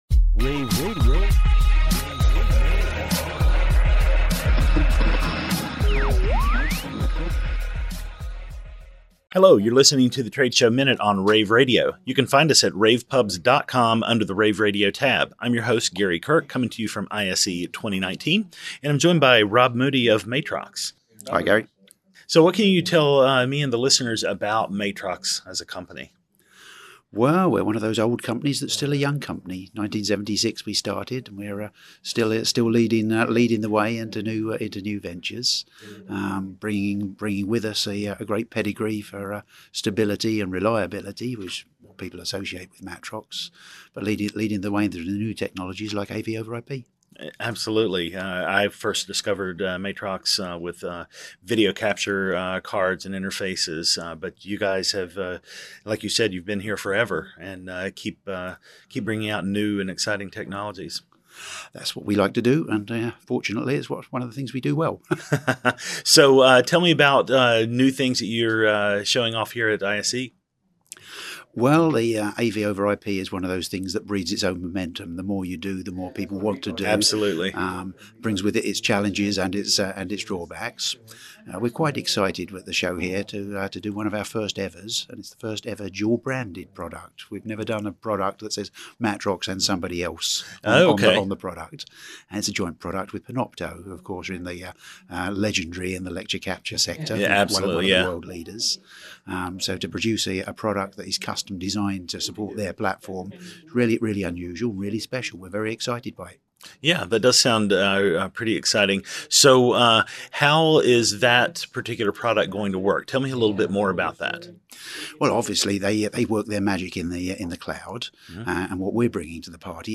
Podcast: Play in new window | Download Subscribe: RSS Categories: ISE , ISE Radio , Radio , rAVe [PUBS] , The Trade Show Minute Tags: ISE 2019 Leave a Comment